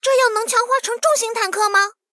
SU-76强化语音.OGG